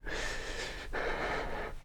Player_UI [15].wav